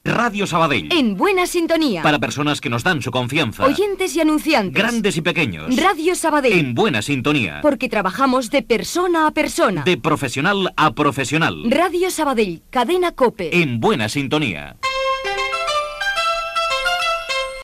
Promoció de l'emissora amb el lema "En buena sintonía"